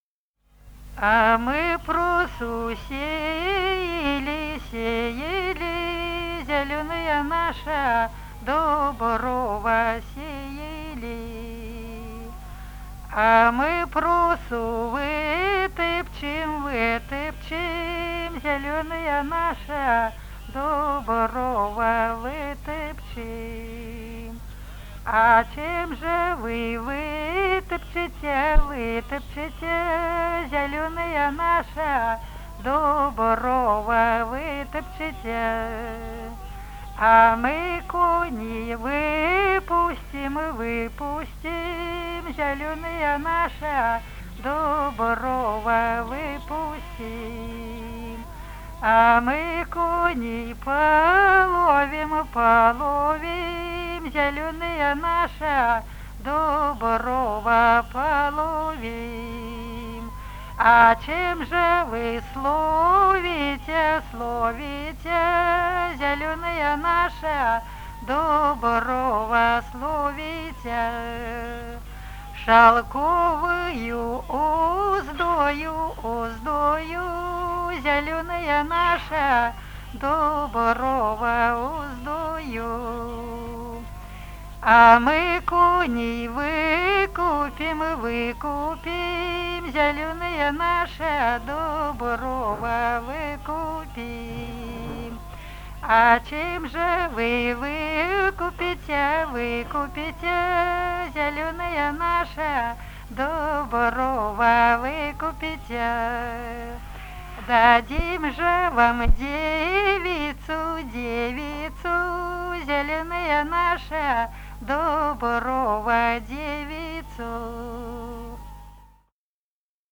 Народные песни Смоленской области
«А мы просо сеяли» (игровая).